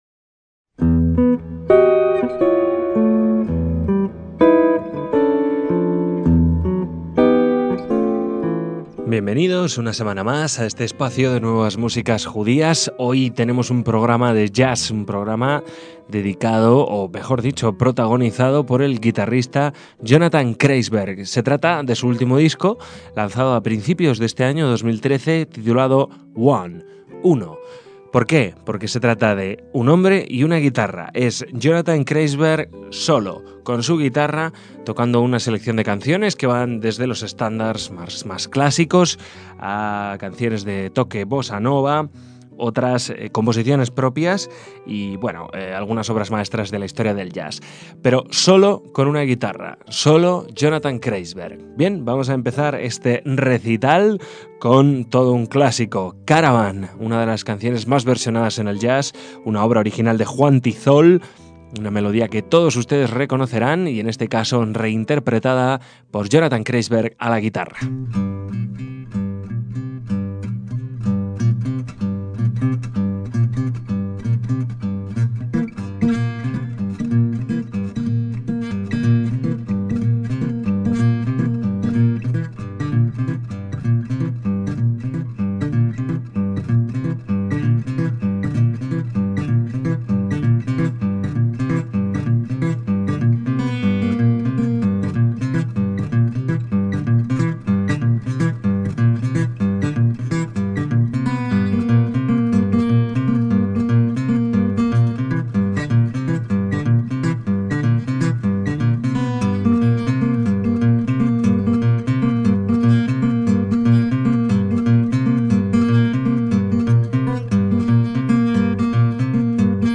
guitarrista estadounidense de jazz